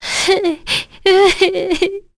Leo-Vox_Sad1.wav